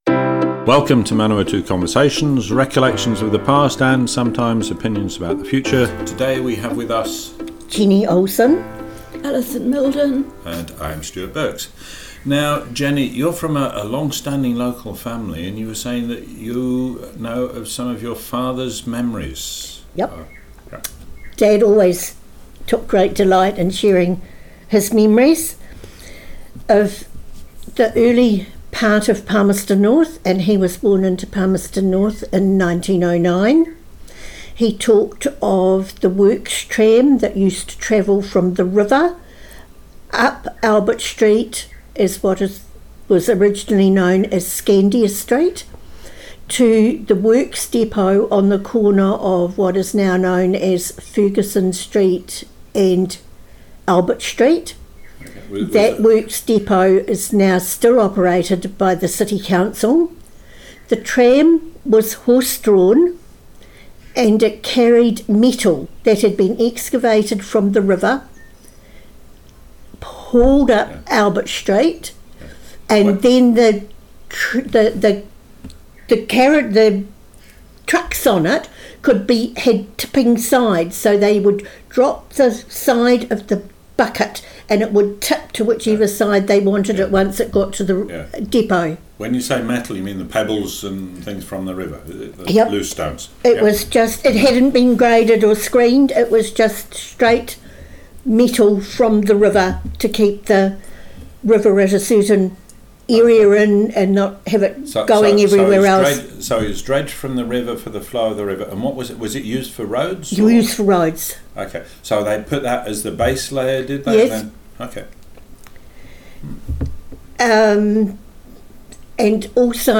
Manawatu Conversations Object type Audio More Info → Description Broadcast on 28th January 2020.
oral history